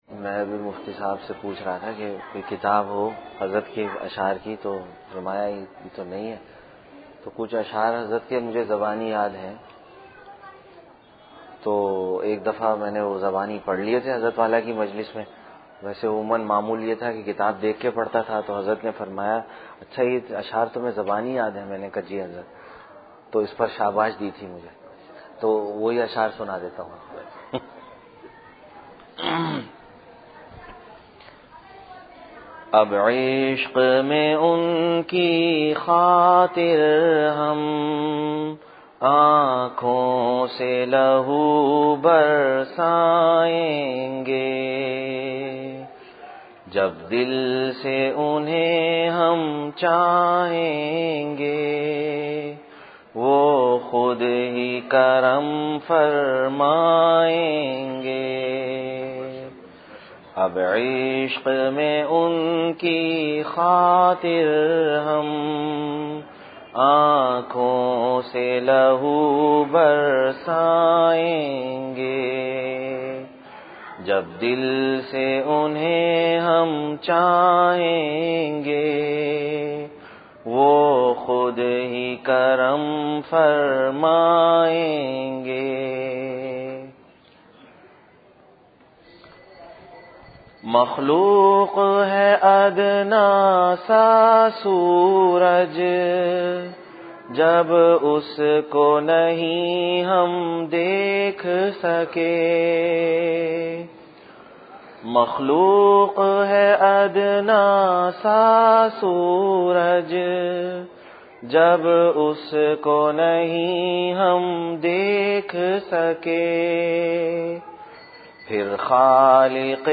15-Jan-2015 After Asar Bayan (Jamia Riyaz ul uloom)